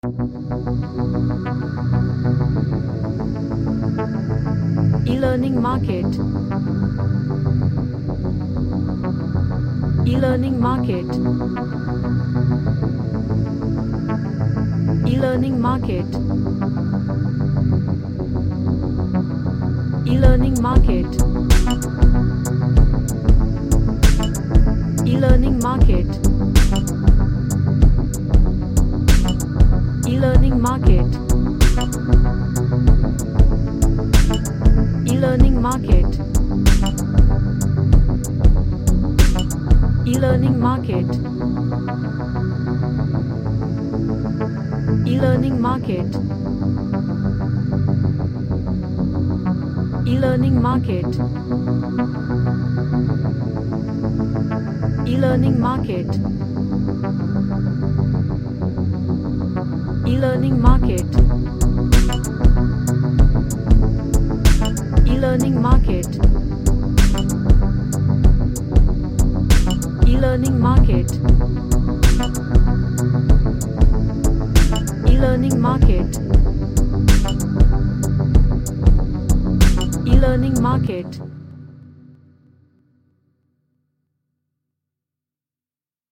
A Dark Ambient track with layers of multiple pads.
Dark / Somber